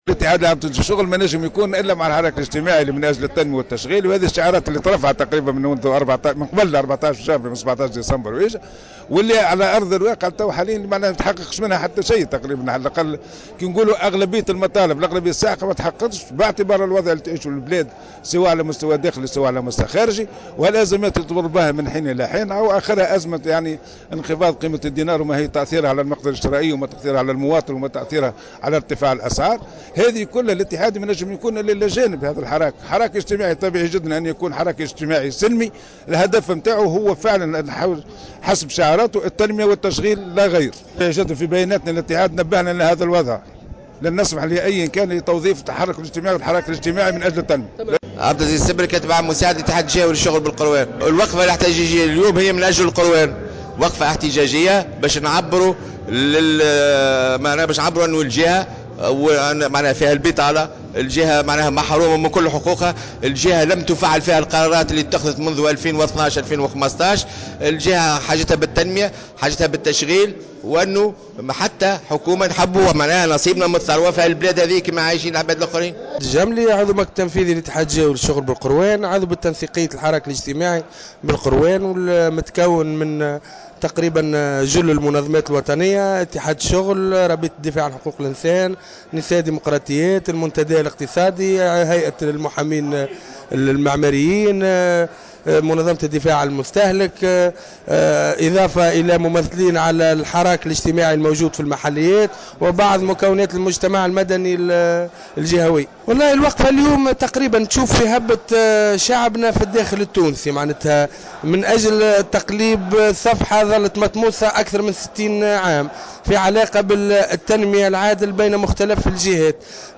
تصريح